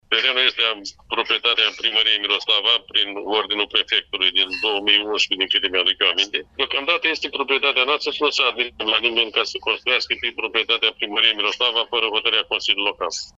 Primarul comunei Miroslava, Dan Niță, a atacat în contencios o hotărâre a Consiliului Local prin care revendică o suprafață de teren de peste 150 de hectare, pe care ar urma să fie amplasat Stadionul municipiului Iași.